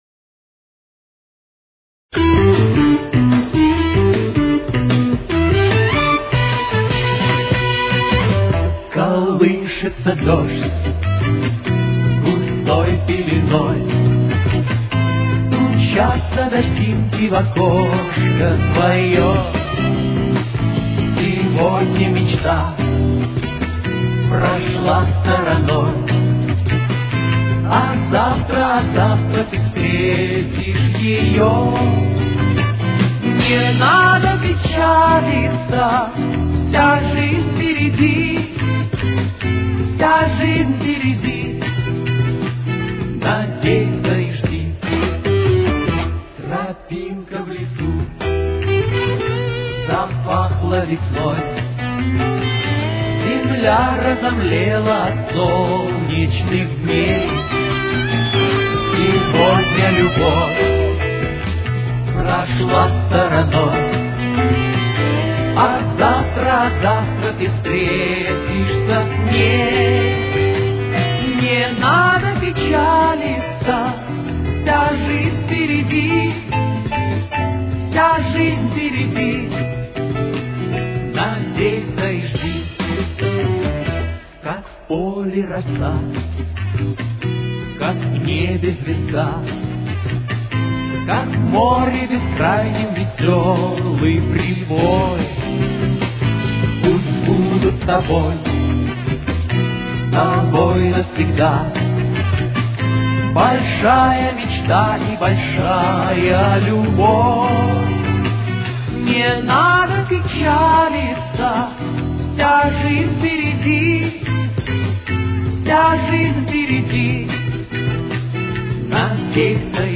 с очень низким качеством